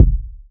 • Urban Kickdrum Sound D# Key 191.wav
Royality free kick single hit tuned to the D# note. Loudest frequency: 96Hz
urban-kickdrum-sound-d-sharp-key-191-8h7.wav